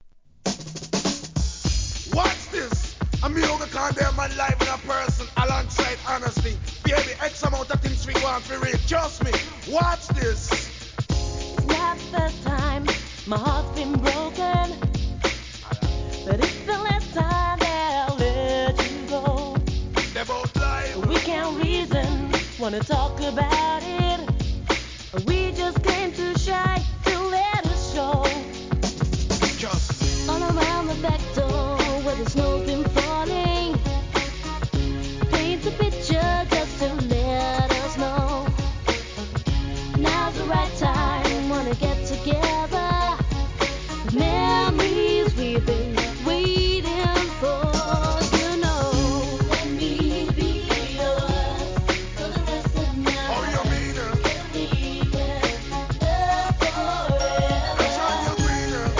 HIP HOP/R&B
のビートにラガMCが絡む使い勝手も良さそうな1994年 R&B!!